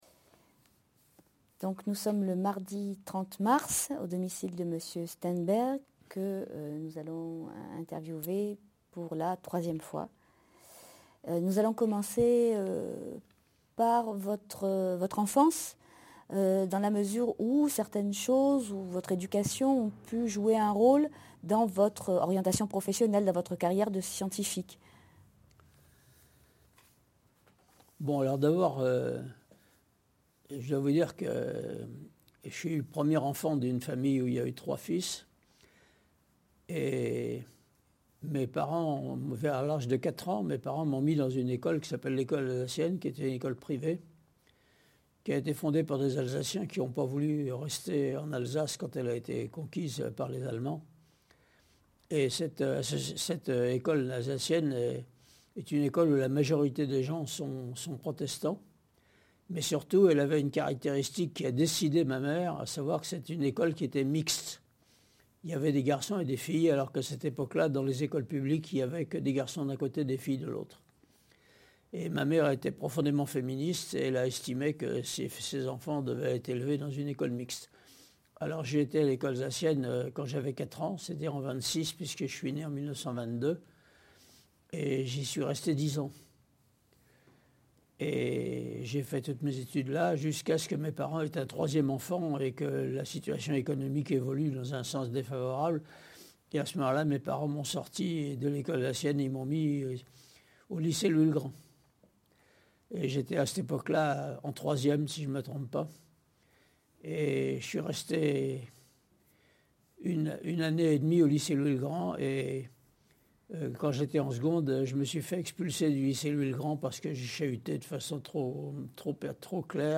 Interviews de Jean-Louis Steinberg, 2009-2010 · OBSPM